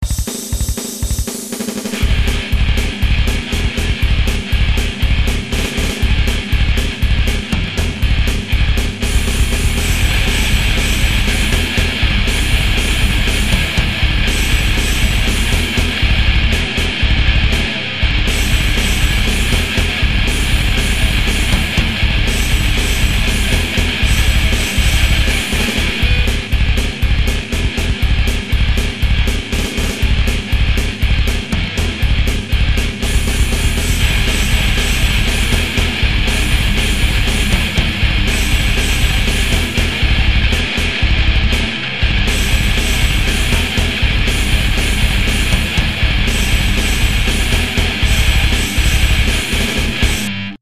I just have these two songs up for a little bit and its just drum and bass for one of them and everything except vocals for the second song but check it out!